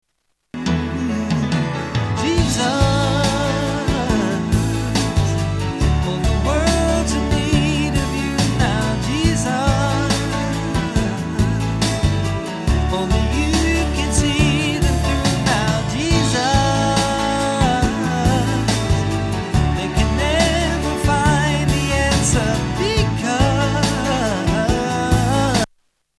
STYLE: R&B
The simplest of devotional songs
has a beautiful piano-driven lilt